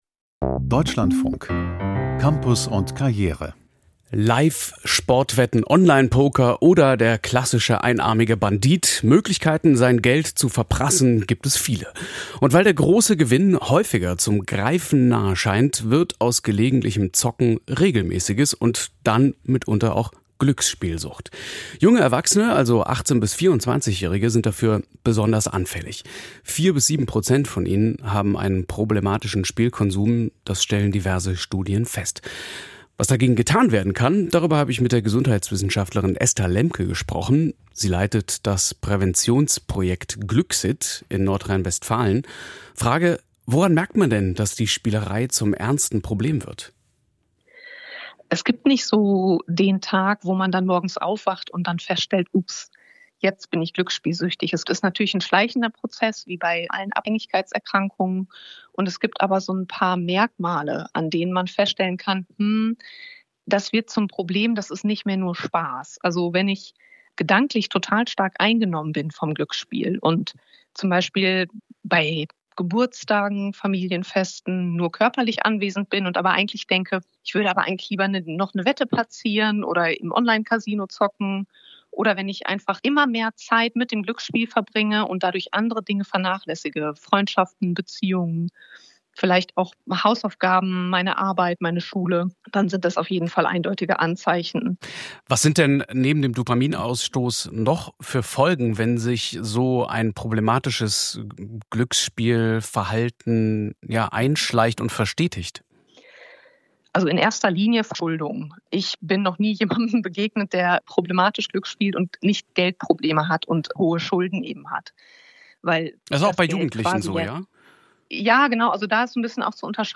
Glücksspielprävention an Berufskollegs - Interview